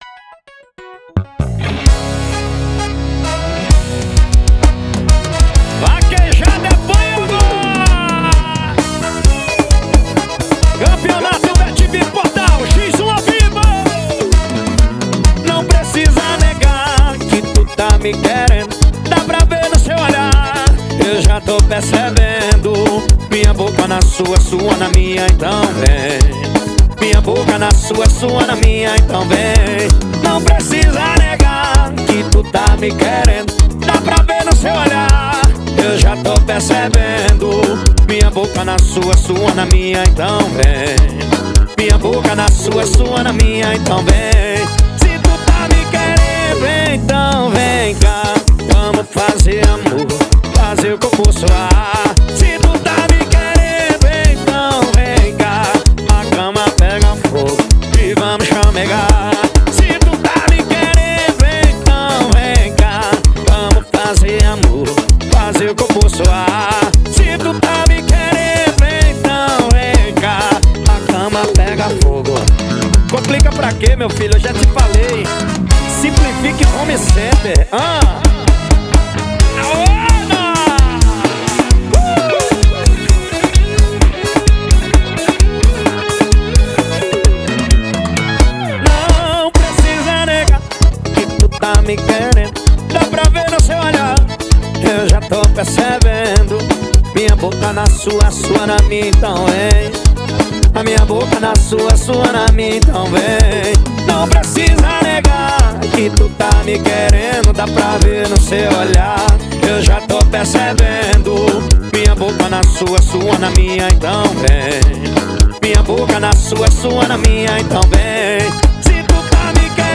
2024-02-14 22:38:24 Gênero: Forró Views